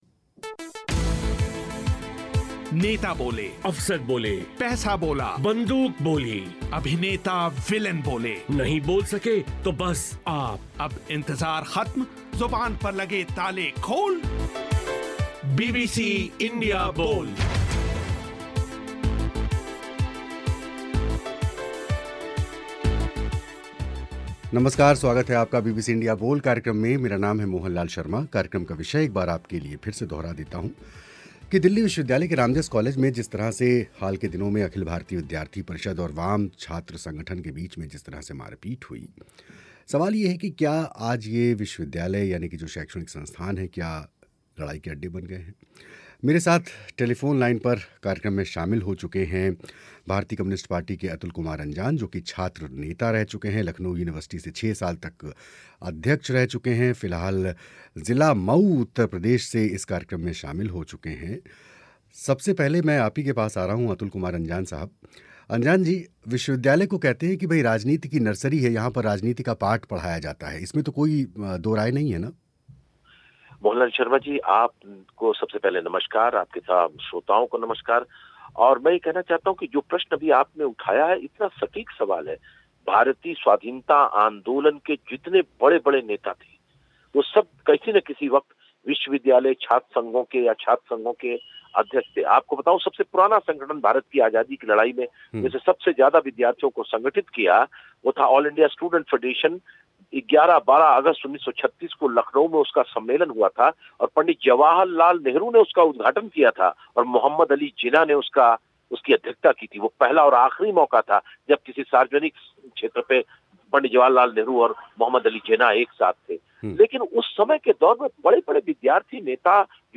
क्या दिल्ली विश्वविद्यालय के रामजस कॉलेज में छात्र संगठनों की लड़ाई इस ओर इशारा नहीं करती? यही विषय पर हुई चर्चा.